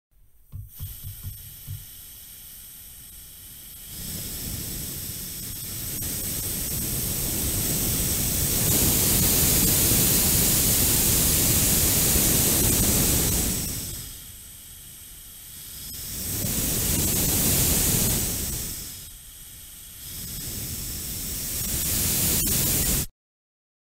Звуки утечки
На этой странице собраны различные звуки утечек: от капающей воды до шипящего газа.